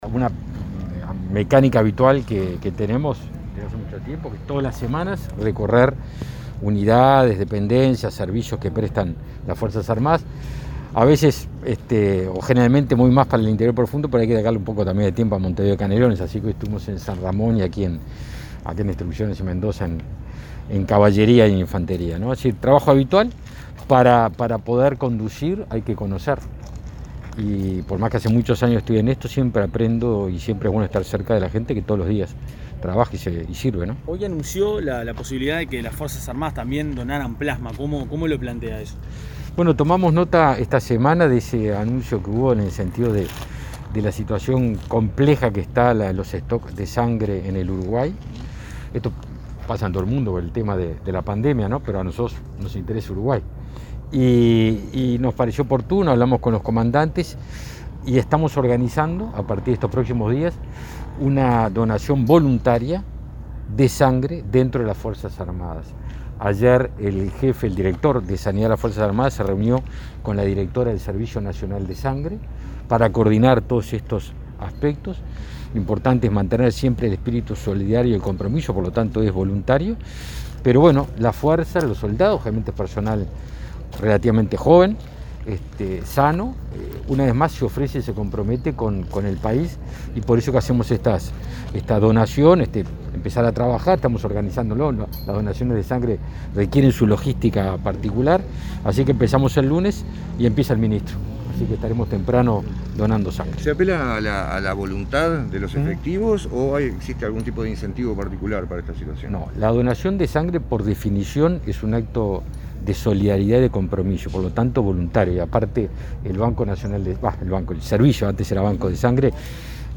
Declaraciones del ministro de Defensa, Javier García, en el Regimiento de Caballería n.° 4